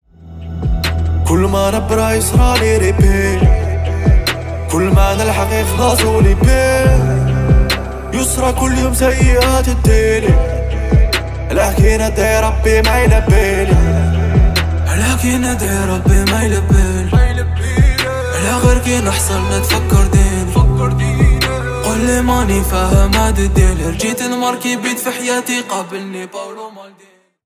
Поп Музыка
тихие